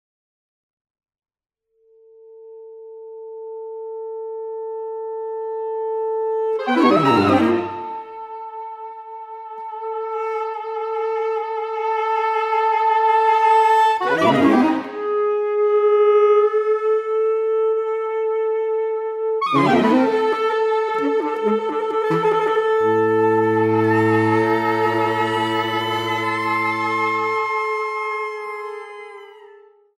at Rose Recital Hall, University of Pennsylvania.